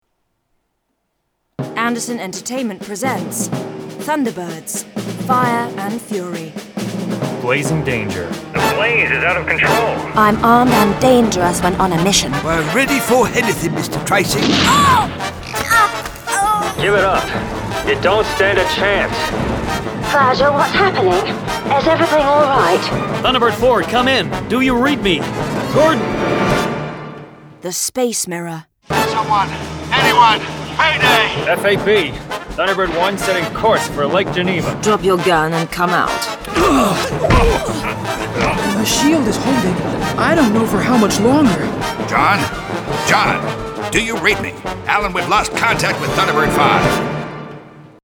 Adapted from the original comic strips, two more thrilling Thunderbirds adventures are brought to life as full-cast audio dramas, as International Rescue embark on perilous missions in The Space Mirror and Blazing Danger…